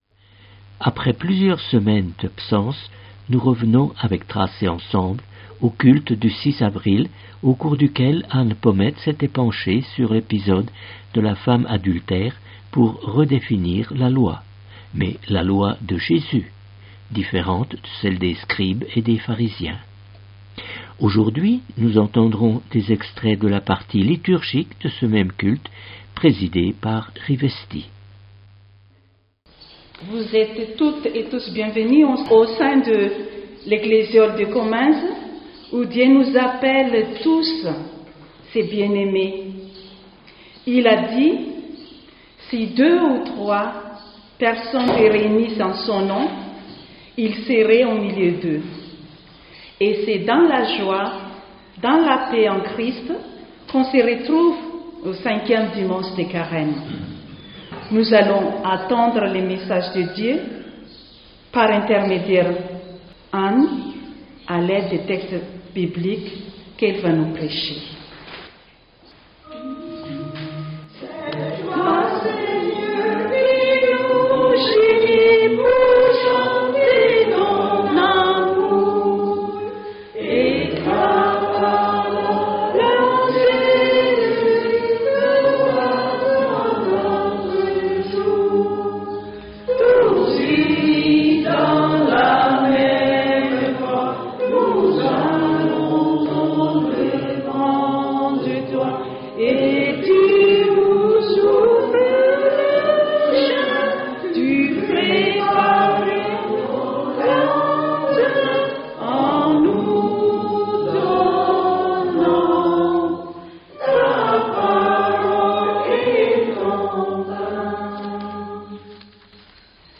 Une partie de la liturgie du culte